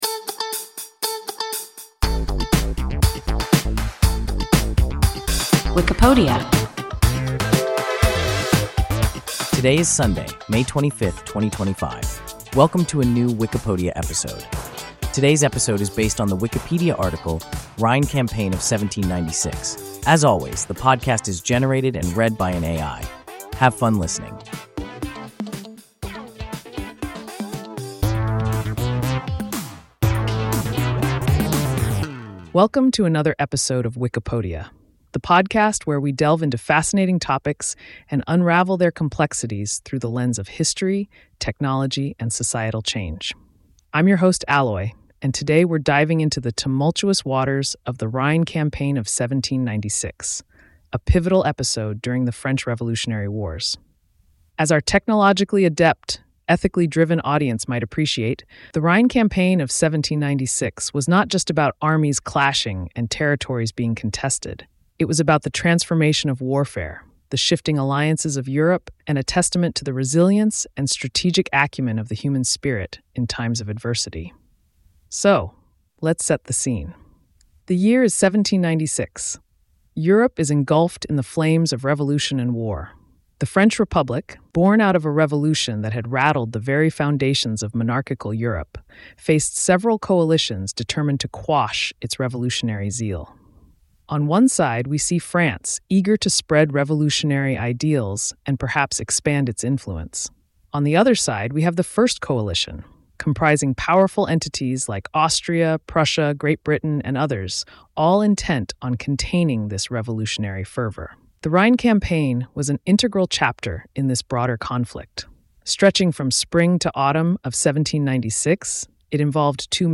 Wikipodia – an AI podcast